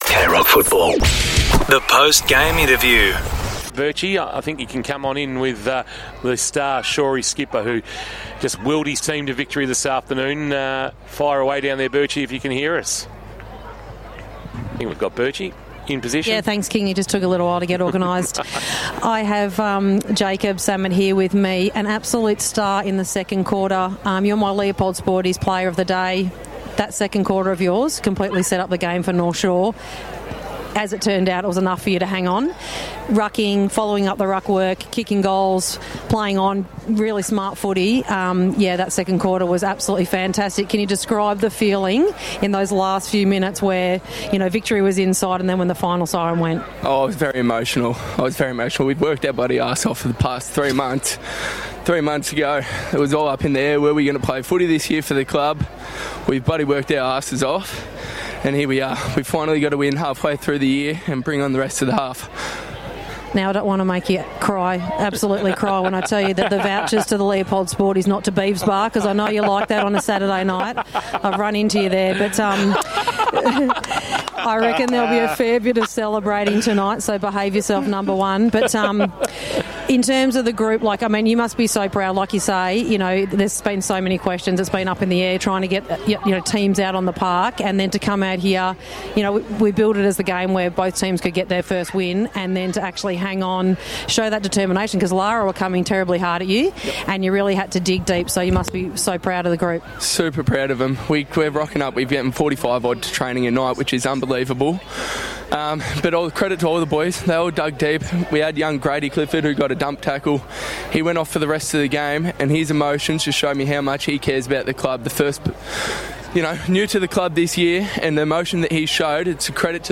2025 - GFNL - Round 9 - Lara vs. North Shore: Post-match interview